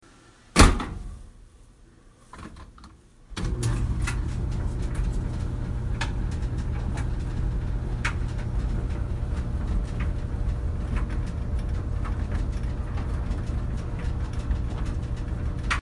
烘干机
Tag: 衣服 洗衣 干衣机 洗衣机